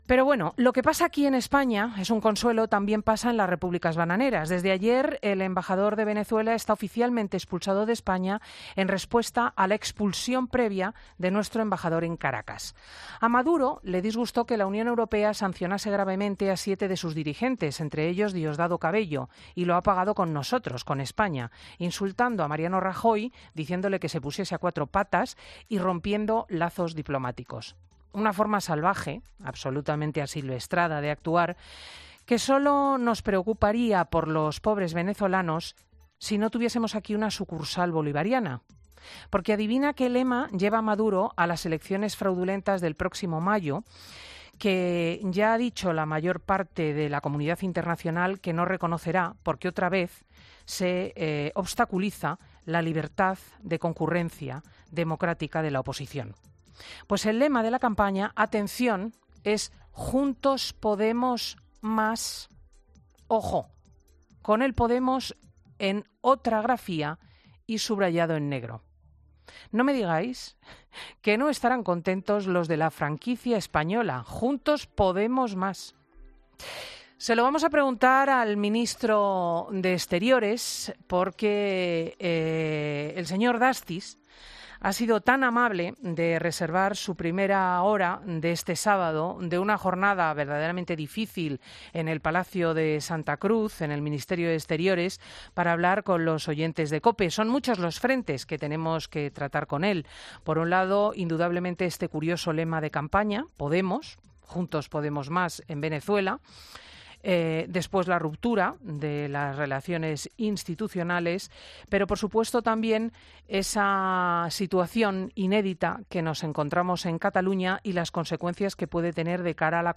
Alfonso Dastis, ministro de Asuntos Exteriores y Cooperación de España, ha asegurado en 'Fin de Semana' con Cristina López Schlichting que las...